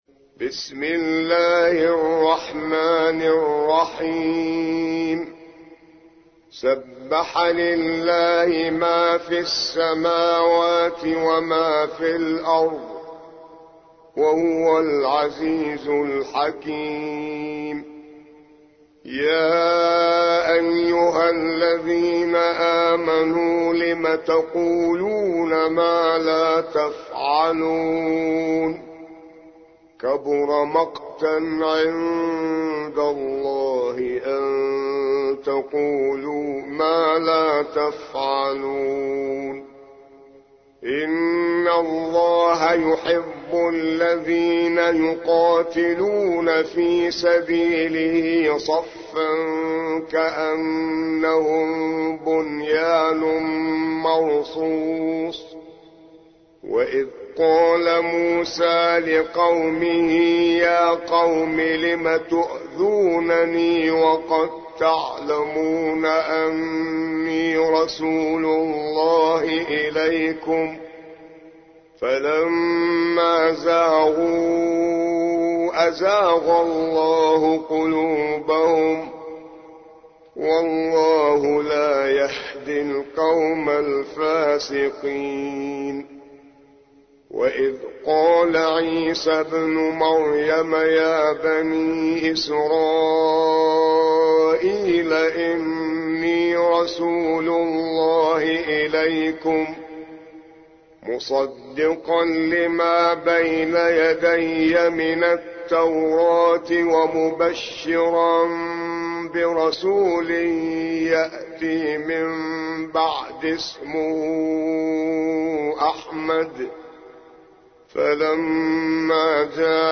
61. سورة الصف / القارئ